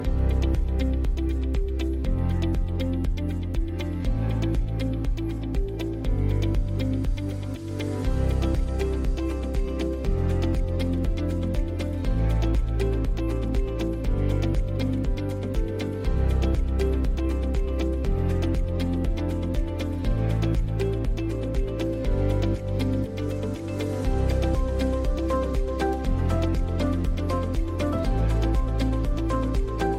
Music-On-Hold Options